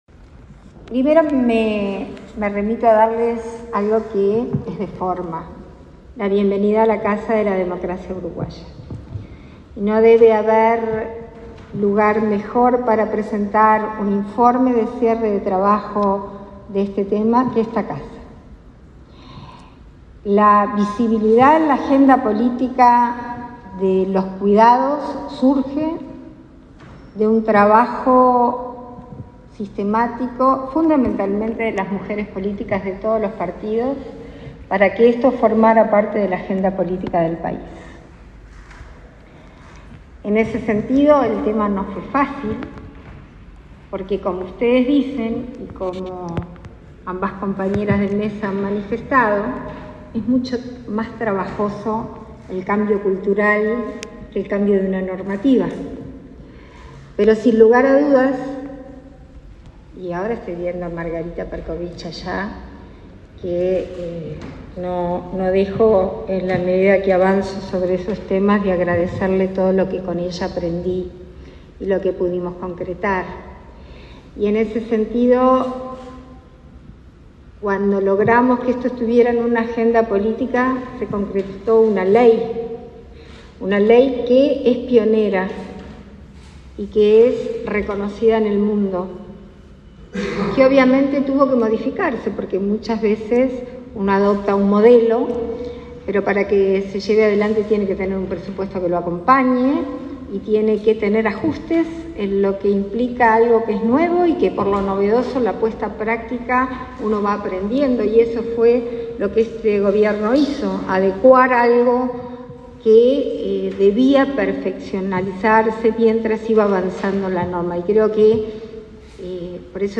Palabras de autoridades en acto por cierre del Mes de los Cuidados
Palabras de autoridades en acto por cierre del Mes de los Cuidados 28/04/2023 Compartir Facebook X Copiar enlace WhatsApp LinkedIn Este viernes 28 en el Palacio Legislativo, se realizó el acto de cierre del Mes de los Cuidados y el lanzamiento de la campaña “Vos también podés cuidar”. La vicepresidenta de la República, Beatriz Argimón; la directora de Inmujeres, Mónica Bottero, y la de Cuidados, Florencia Krall, destacaron la importancia de la temática.